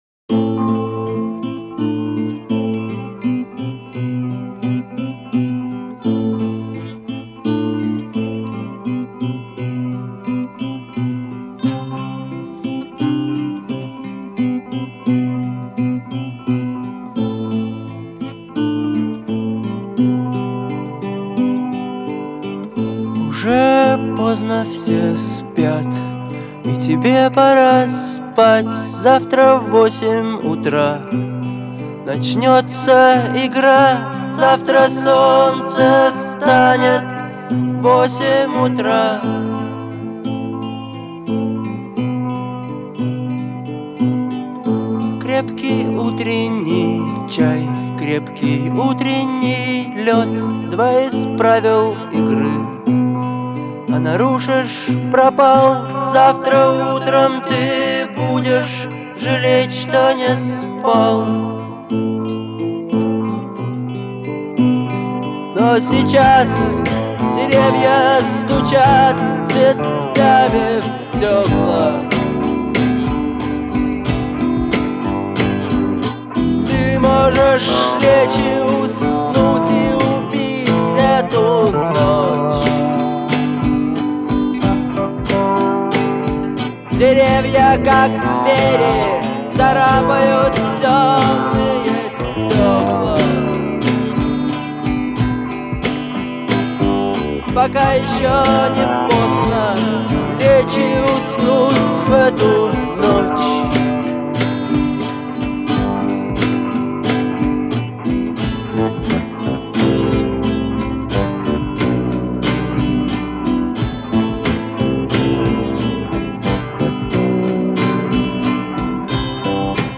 запоминающиеся гитарные рифы